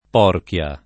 Porchia [ p 0 rk L a ]